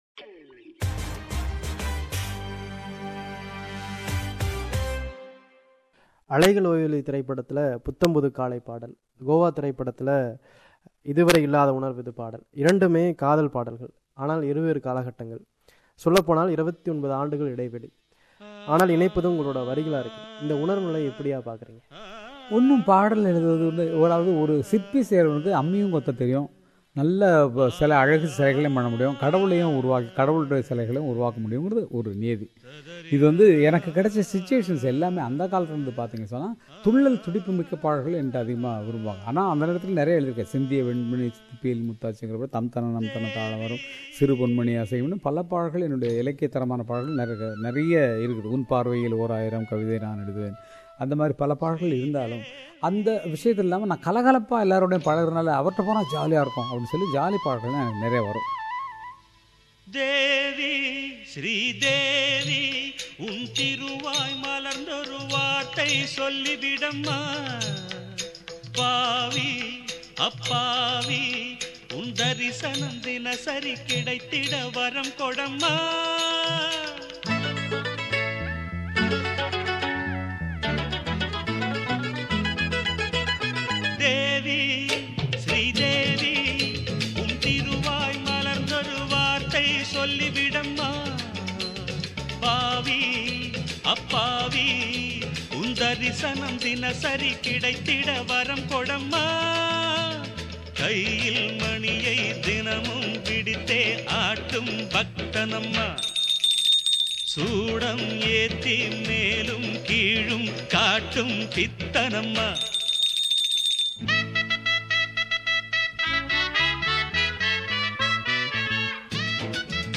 An exclusive interview with Gangai Amaran – Part 3